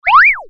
バスト演出 勢いよく現れる 11
/ F｜演出・アニメ・心理 / F-18 ｜Move コミカルな動き
プヨーォン